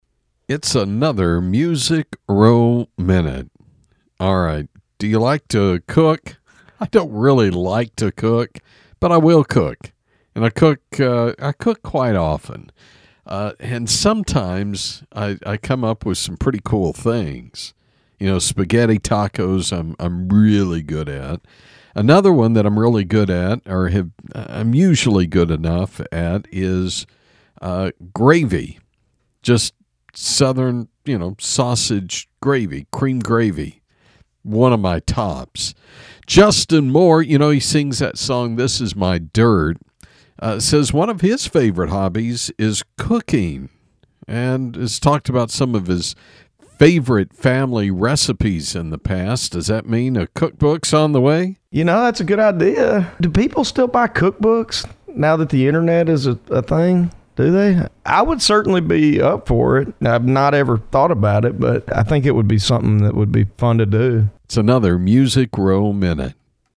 Music Row Minute is a daily radio feature on 106.1FM KFLP